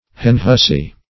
Henhussy \Hen"hus`sy\, n. A cotquean; a man who intermeddles with women's concerns.